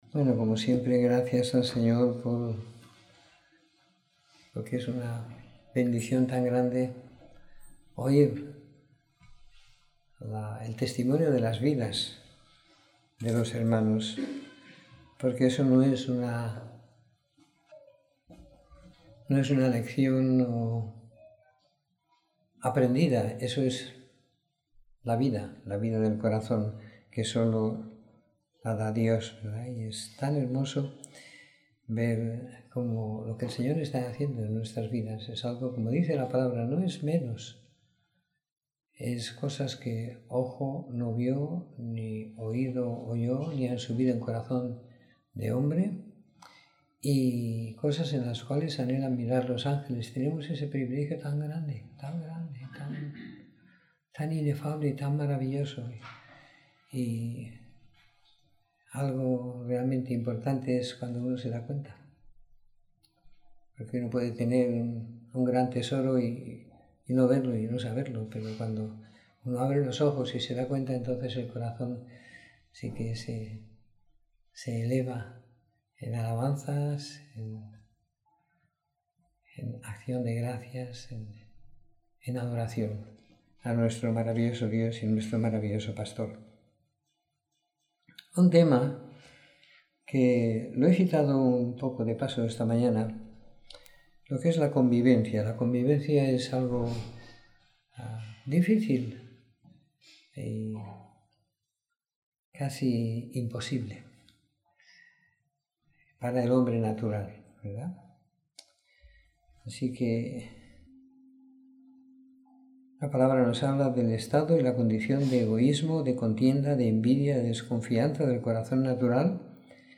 Reunión de Domingo por la Tarde